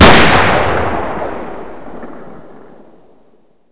sol_reklam_link sag_reklam_link Warrock Oyun Dosyalar� Ana Sayfa > Sound > Weapons > AI_AW Dosya Ad� Boyutu Son D�zenleme ..
WR_Fire.wav